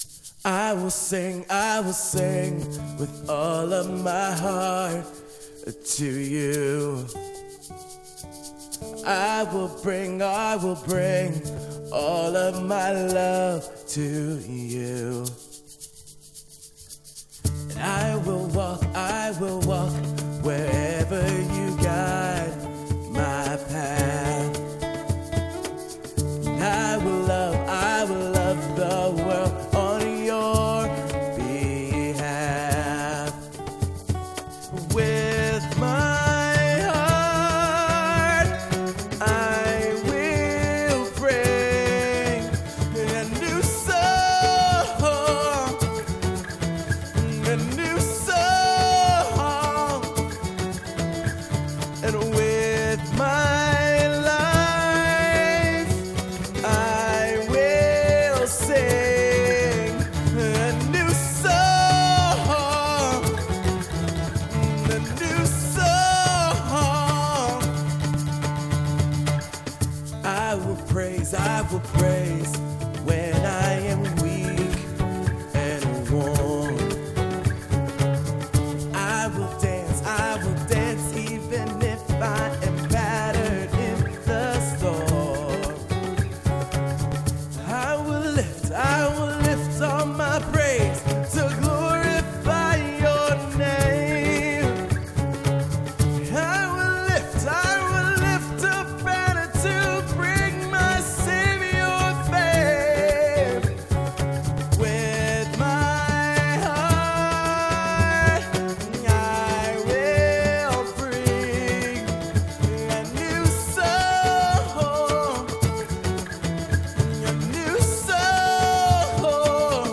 • Genre: Acoustic / Rock